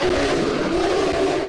PixelPerfectionCE/assets/minecraft/sounds/mob/polarbear/death2.ogg at mc116